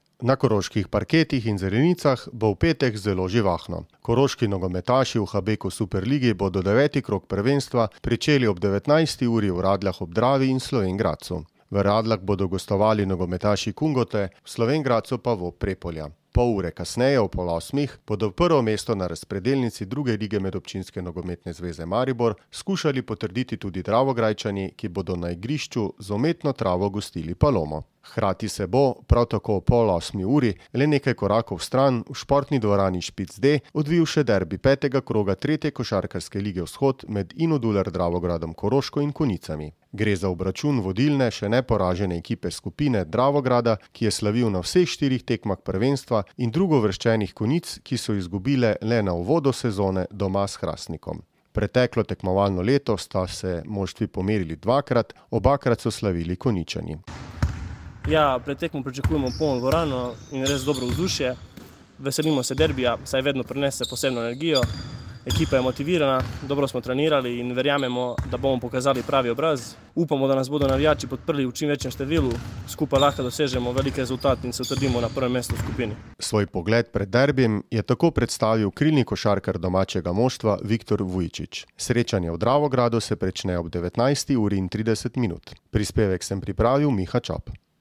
Svoj pogled pred derbijem je v zvočni izjavi predstavil krilni košarkar domačega moštva